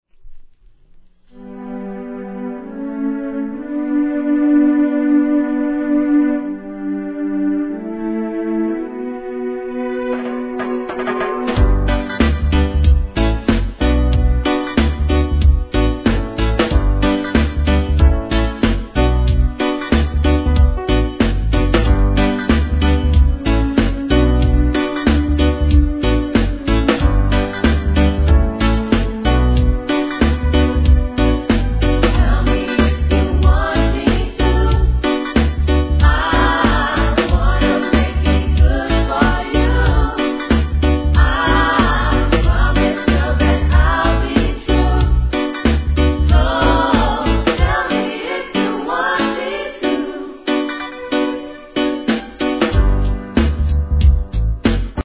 12inch
REGGAE